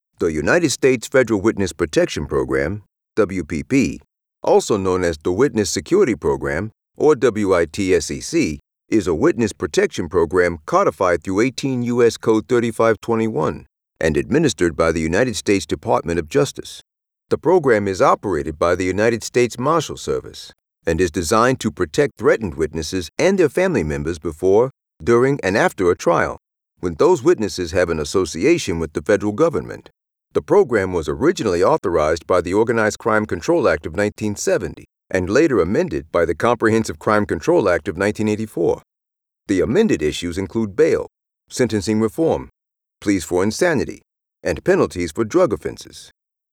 Warm, wise and comforting.
New York Composite Style
General American, African American, Western, South and New York accents.
Middle Aged